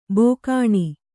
♪ bōkāṇi